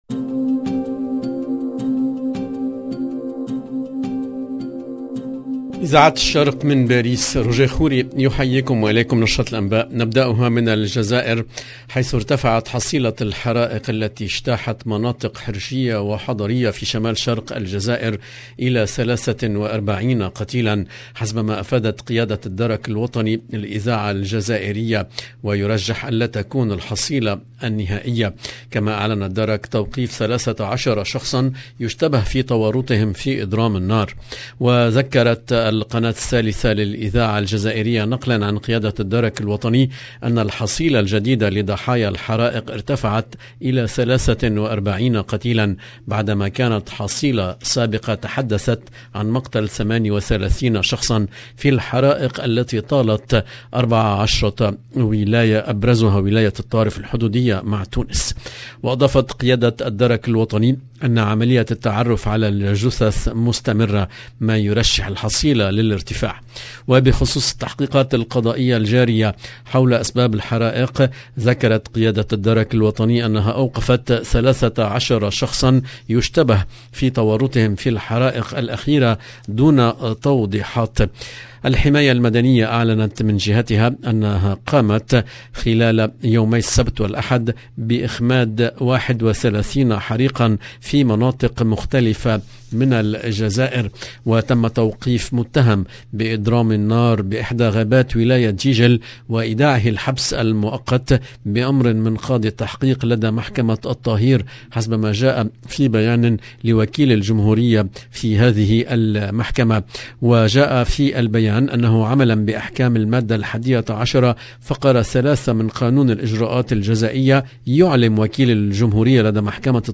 EDITION DU JOURNAL DU SOIR EN LANGUE ARABE DU 22/8/2022